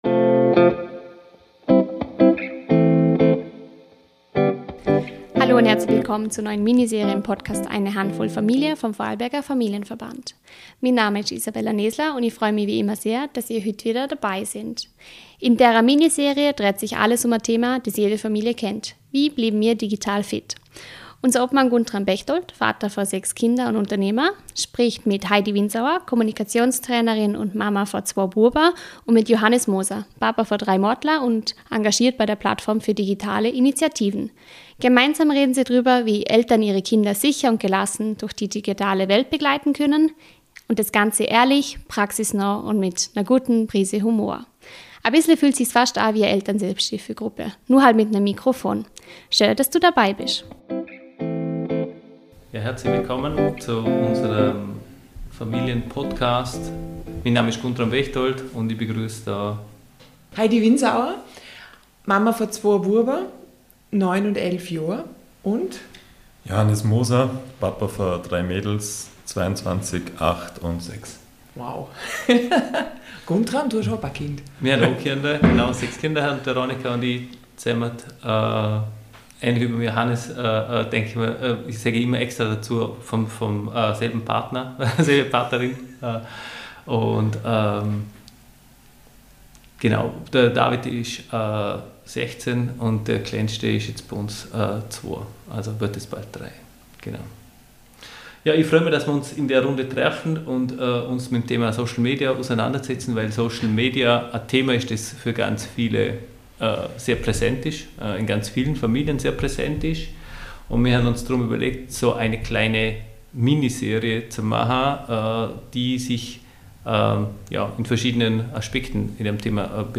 Sie sprechen über Vorbilder, gemeinsame Regeln, den richtigen Umgang mit Bildschirmzeit und warum echtes Interesse oft wichtiger ist als Kontrolle. Eine ehrliche, humorvolle und praxisnahe Folge, die zeigt: Wir müssen keine Technikprofis sein.